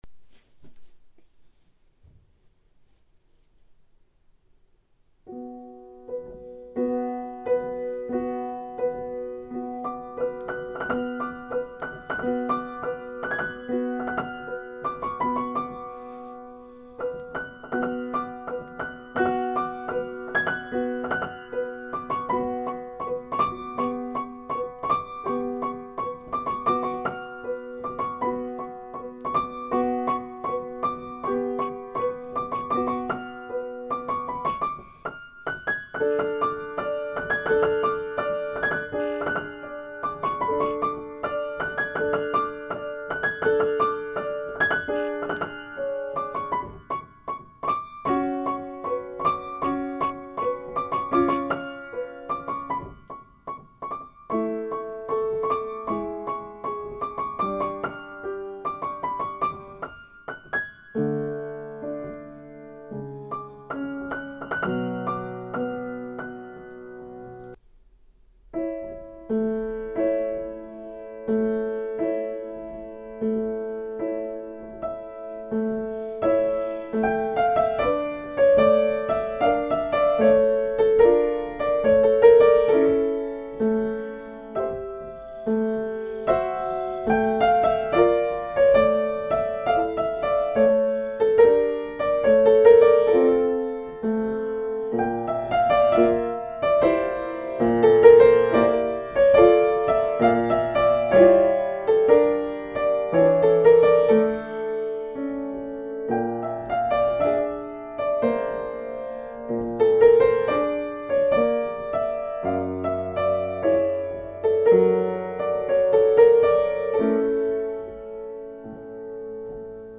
In my late twenties, I performed a solo piano recital for a fundraiser for a small, Unity Church community that was hoping to build a sanctuary.